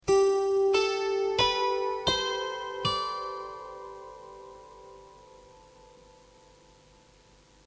VIISIKIELINEN KANTELE